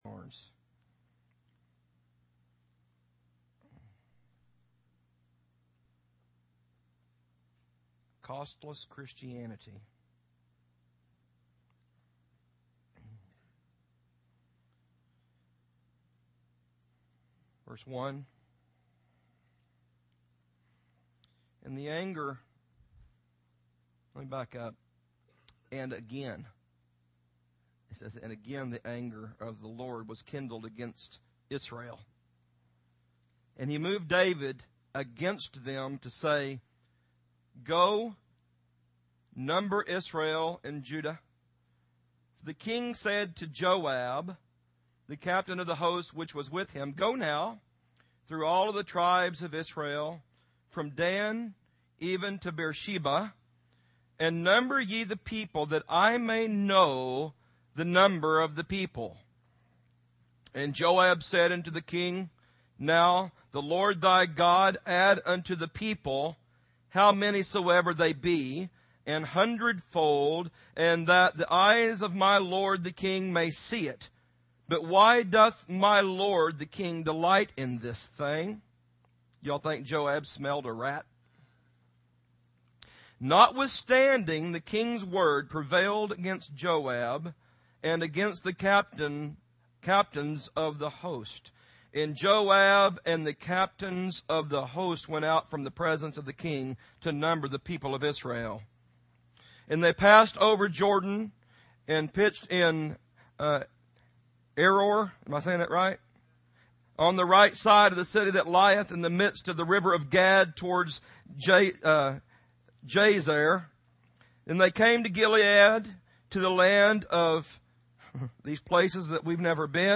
In this sermon, the preacher emphasizes the importance of not letting the affairs of this life distract or disqualify believers from serving God. He encourages the audience to prioritize their love for God and the authorities He has placed in their lives.